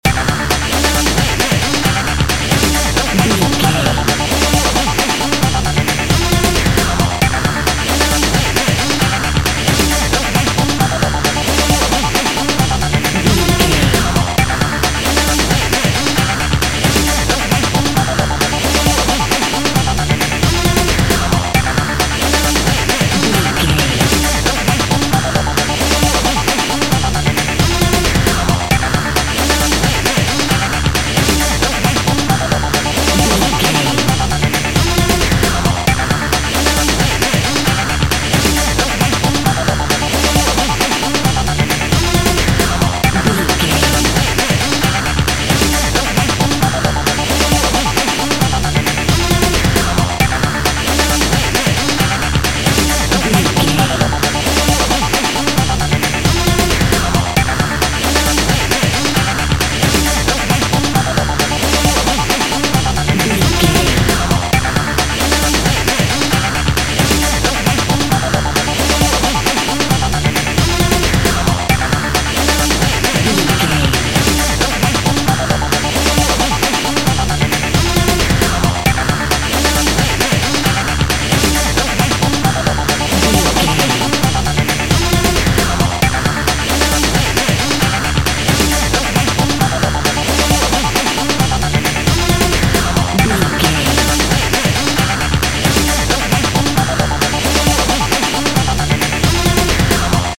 Powerful Breakbeat Music Cue.
Aeolian/Minor
Fast
frantic
driving
energetic
hypnotic
dark
drum machine
Drum and bass
electronic
sub bass
synth lead